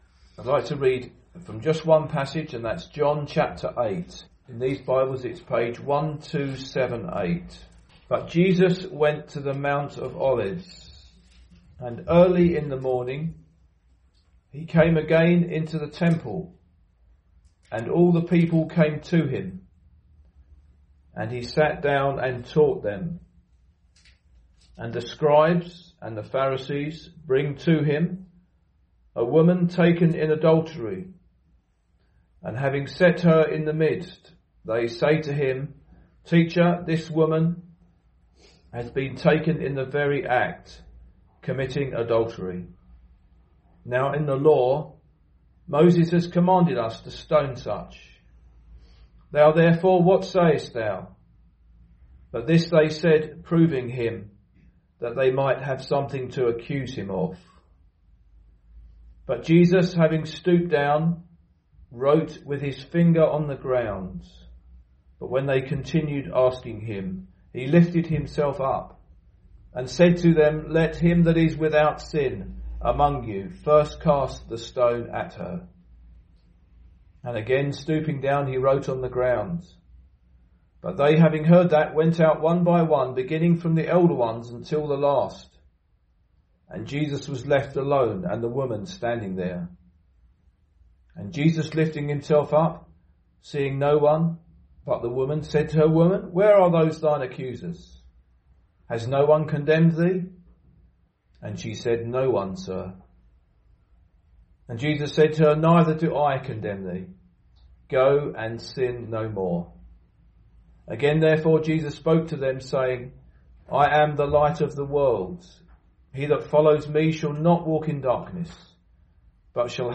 The Gospel is preached with an invitation form Jesus himself and he says "Come follow me".